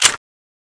Index of /server/sound/weapons/tfa_cso/m95tiger